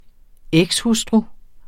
Udtale [ ˈεgs- ]